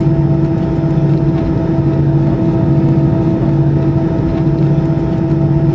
cfm-idle2.wav